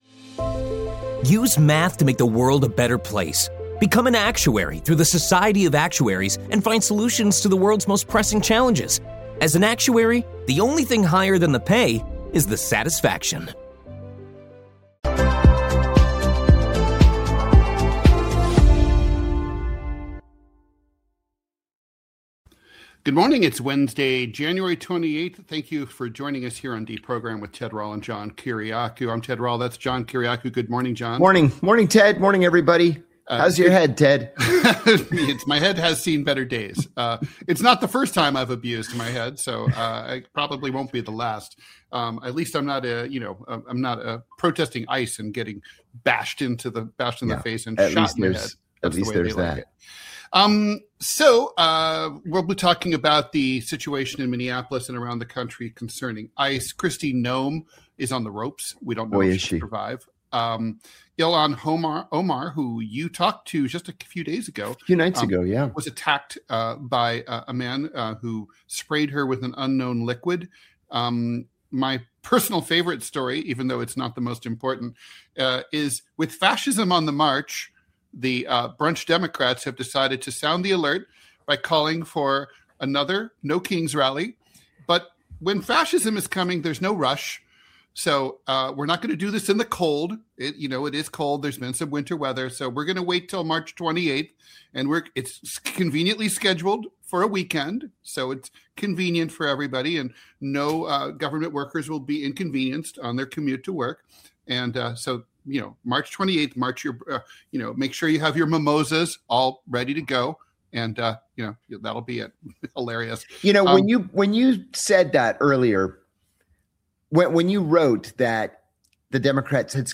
Political cartoonist Ted Rall and CIA whistleblower John Kiriakou deprogram you from mainstream media every weekday at 9 AM EST.